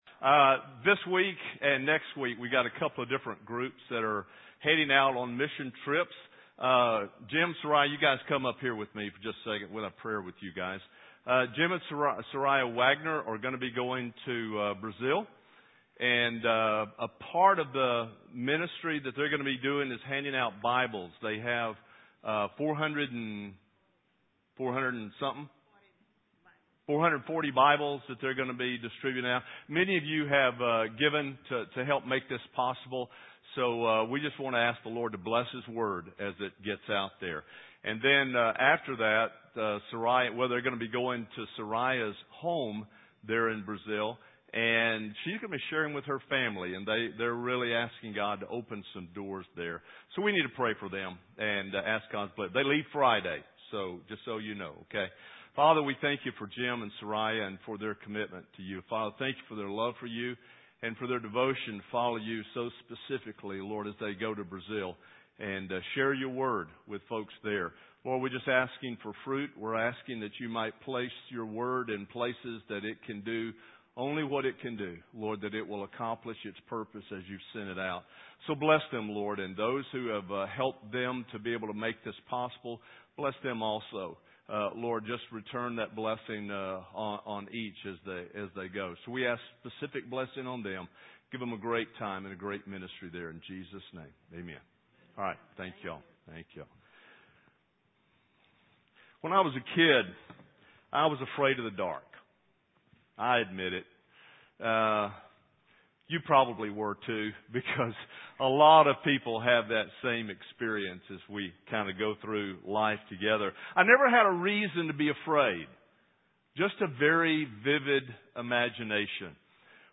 North Shore Church Messages